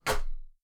toasterstep2.wav